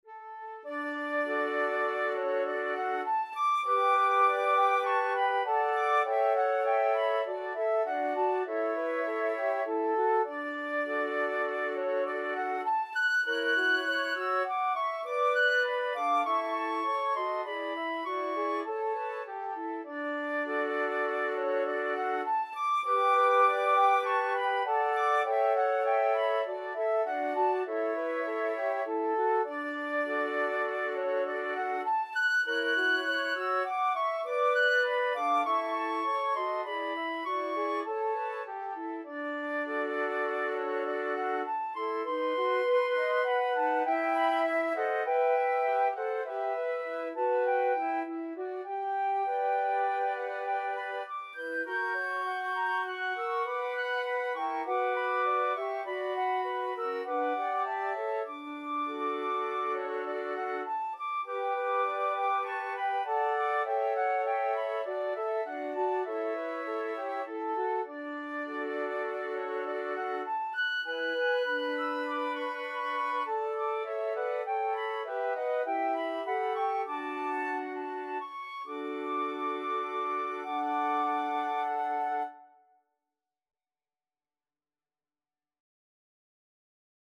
Flute 1Flute 2Flute 3Flute 4
4/4 (View more 4/4 Music)
D major (Sounding Pitch) (View more D major Music for Flute Quartet )
Flute Quartet  (View more Intermediate Flute Quartet Music)
Classical (View more Classical Flute Quartet Music)
schumann_reverie_4FL.mp3